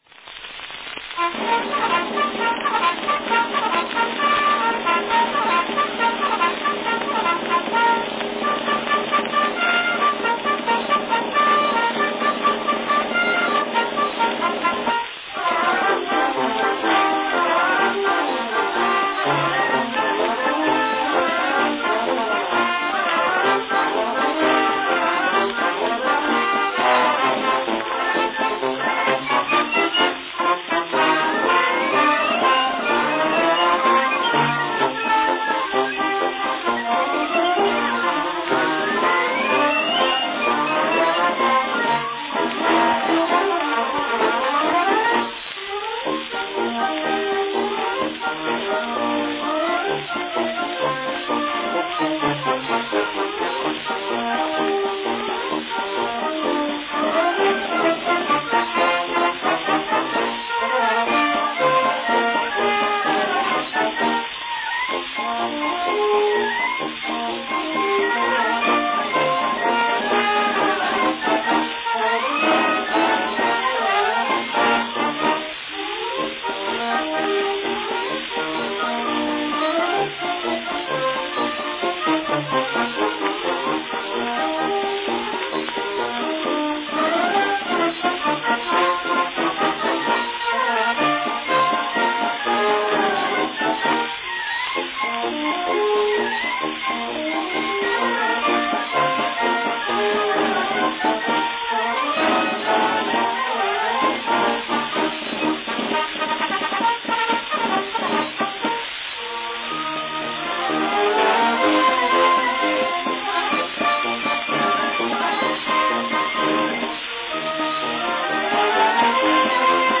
From a 1905 Columbia disc record.